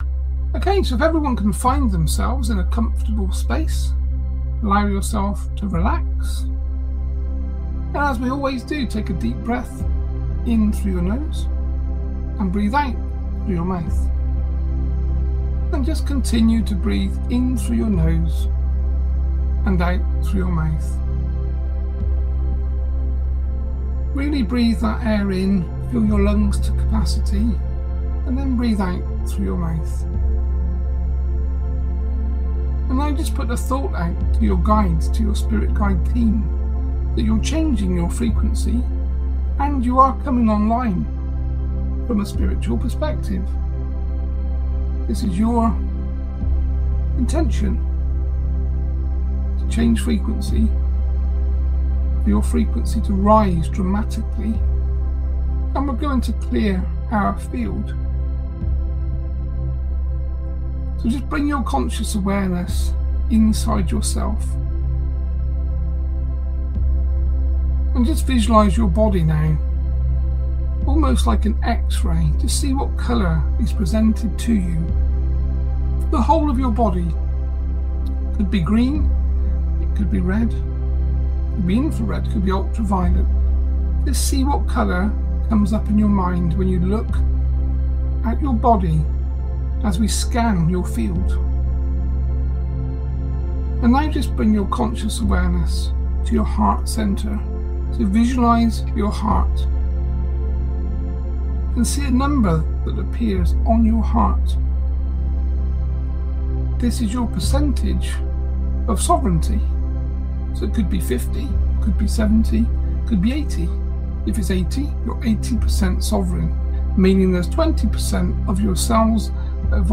8 Min Activate Your Light Worker Frequency Meditation